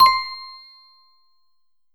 mixkit-correct-answer-tone-2870.mp3